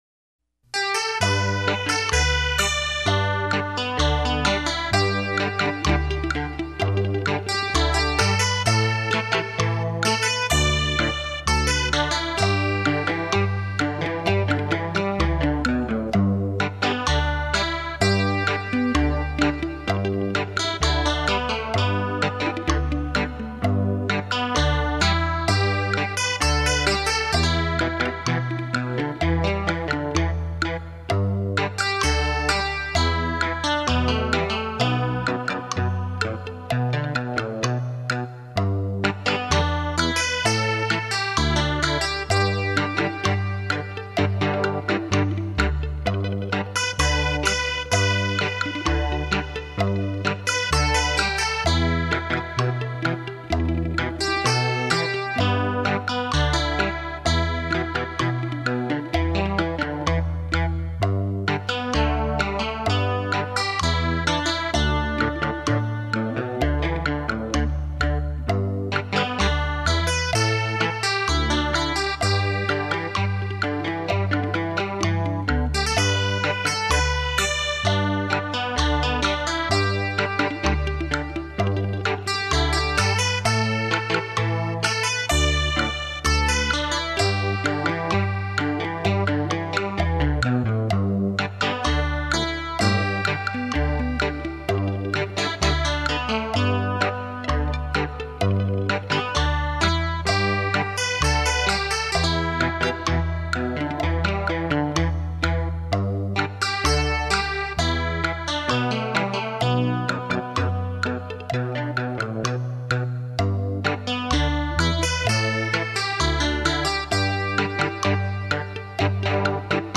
汽车音响测试碟
立体音声 环绕效果
音响测试专业DEMO碟 让您仿如置身现场的震撼感受